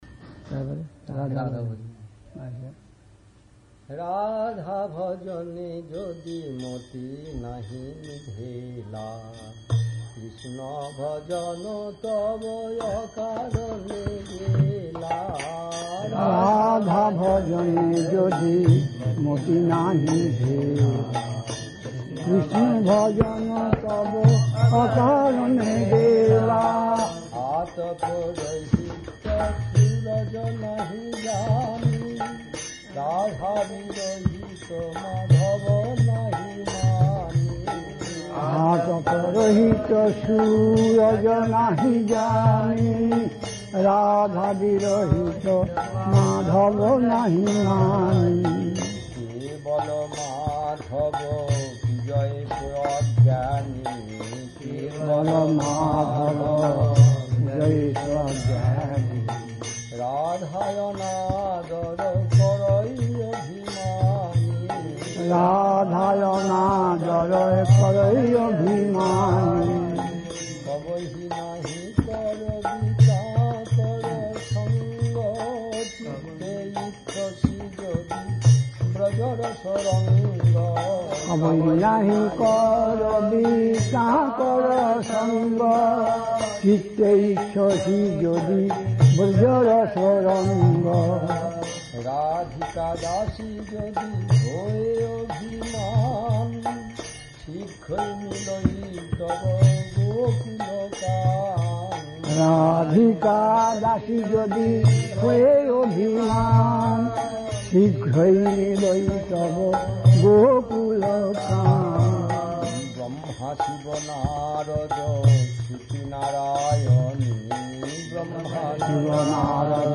It's being posted as vintage classic recordings.
Place: SCSMath Nabadwip
Kirttan